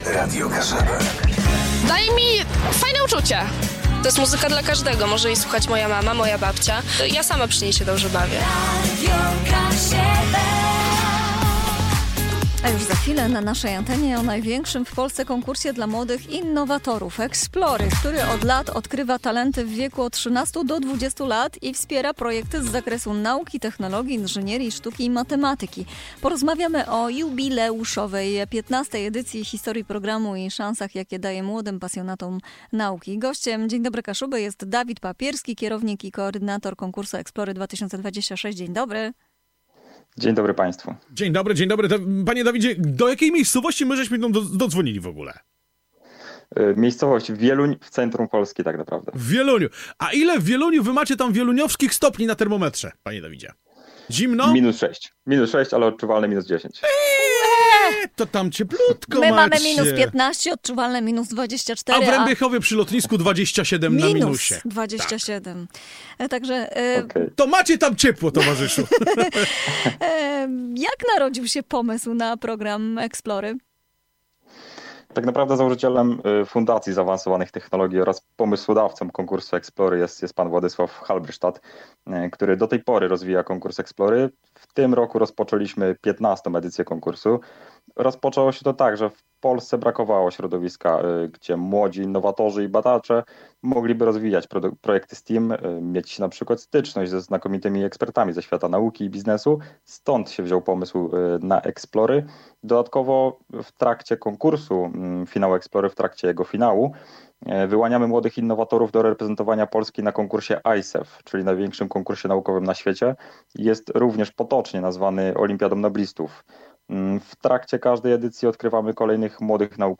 W programie „Dzień Dobry Kaszëbë” nasz gość zdradził, dlaczego warto wysłać zgłoszenie do 15 marca i jakie niezwykłe perspektywy otwierają się przed innowatorami w wieku od 13 do 20 lat.